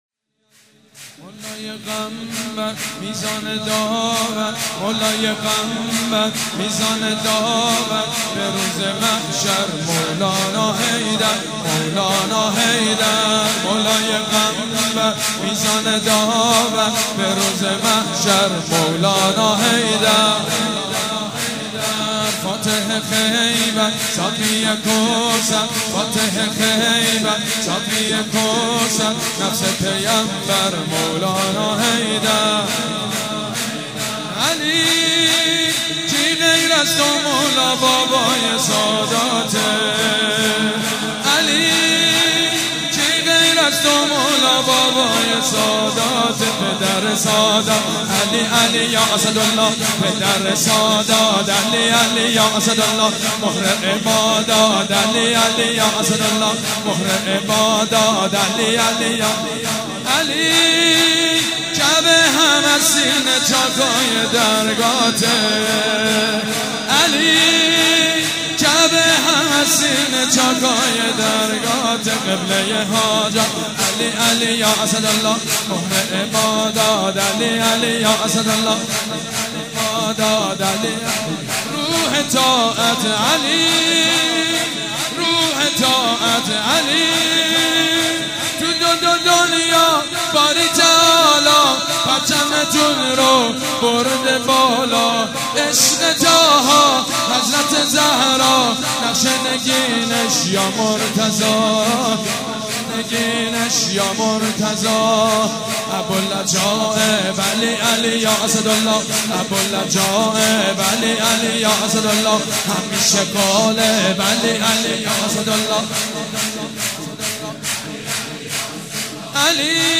سرود: مولای قنبر میزان داور